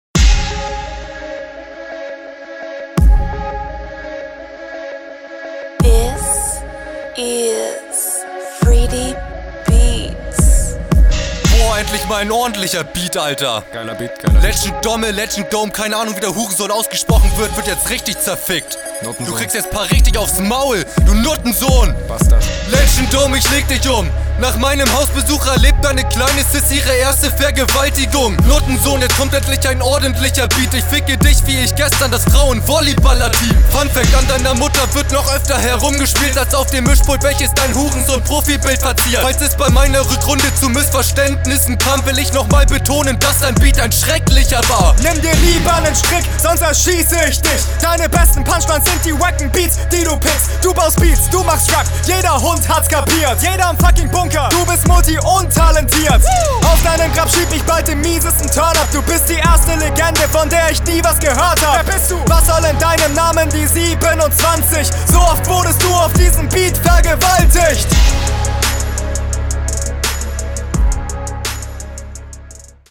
JBB-Type Beat yeah! Flow: Du rappst auf dem Beat wieder sehr cool.